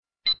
scan-success.mp3